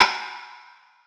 G.O.O.D. Rim.aif